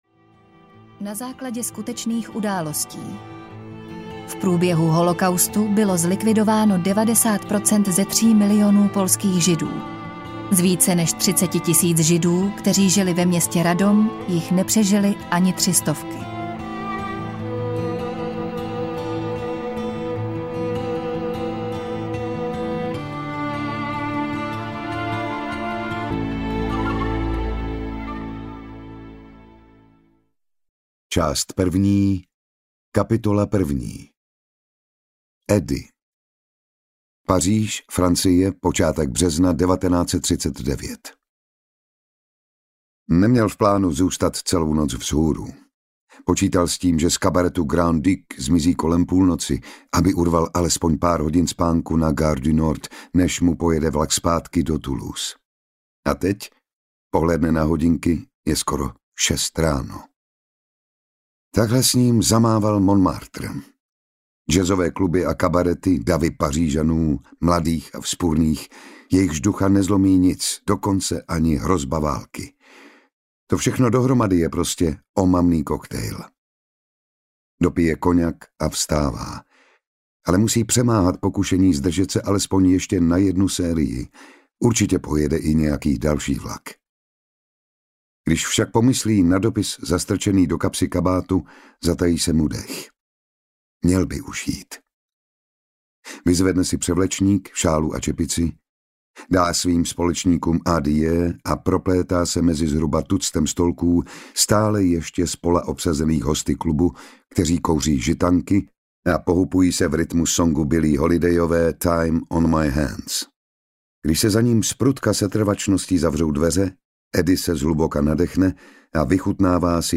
Měli jsme štěstí audiokniha
Ukázka z knihy
meli-jsme-stesti-audiokniha